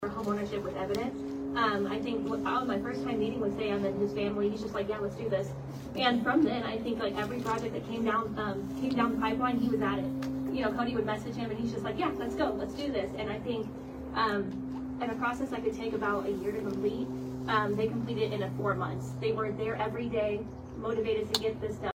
Manhattan Area Habitat for Humanity holds ribbon cutting ceremony for local family